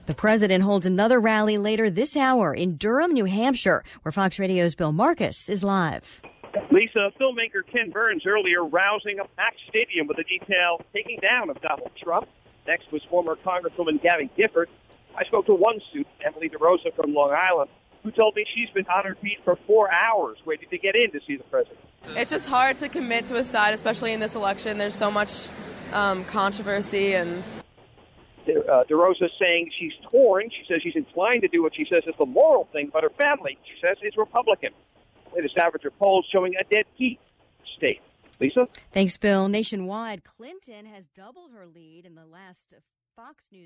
Waiting to get into the Wittemore Center Arena
4PM LIVE –